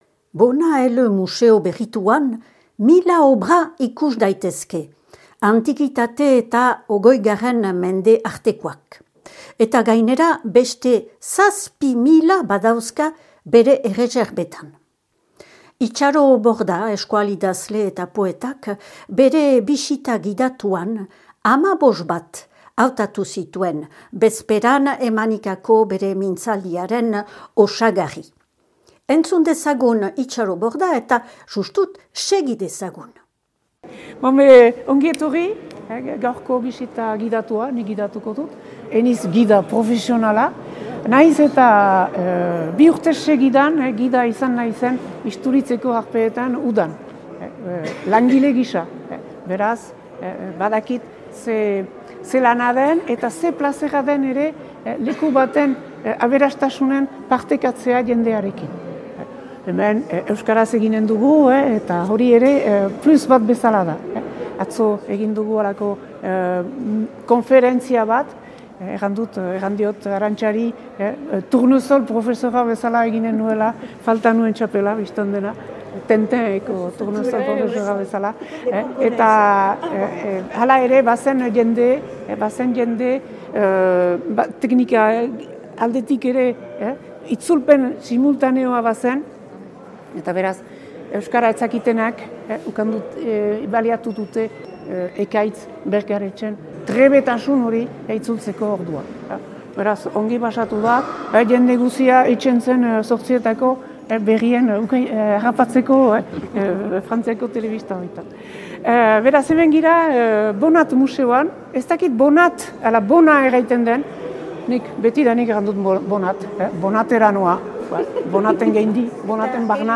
Baionako Arte Ederren museo berrituaren bisita gidatua